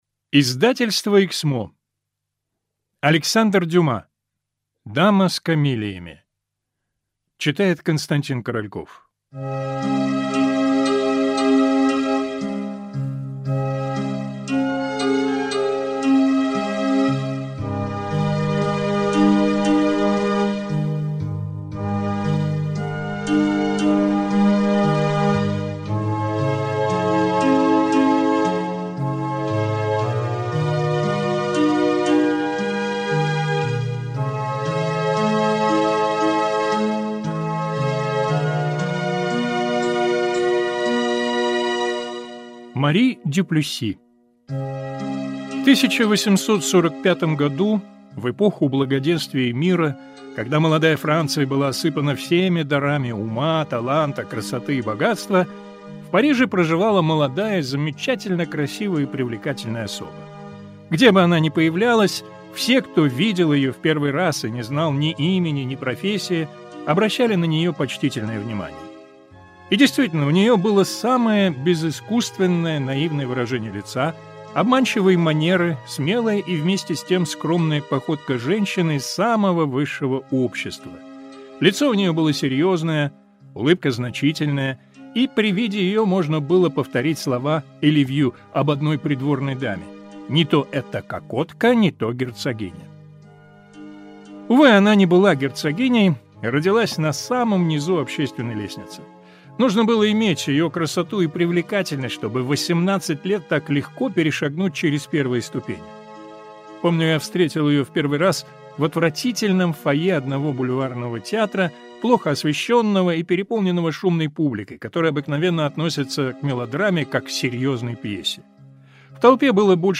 Аудиокнига Дама с камелиями | Библиотека аудиокниг
Прослушать и бесплатно скачать фрагмент аудиокниги